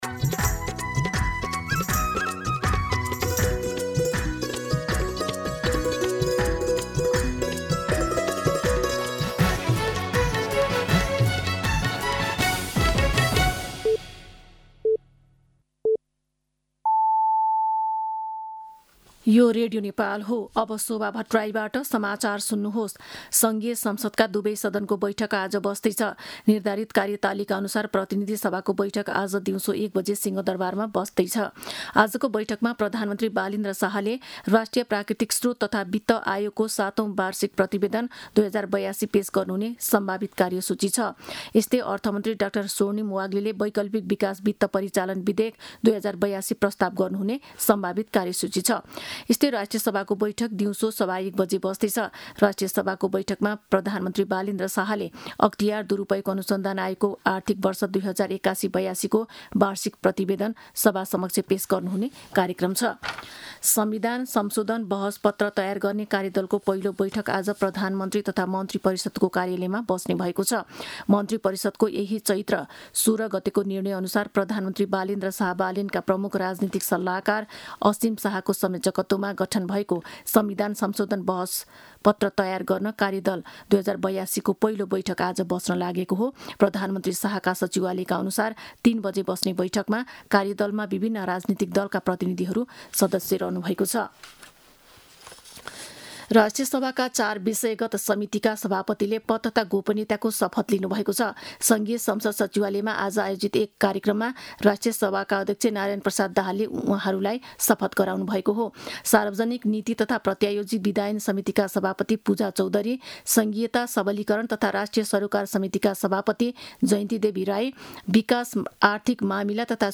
मध्यान्ह १२ बजेको नेपाली समाचार : २५ चैत , २०८२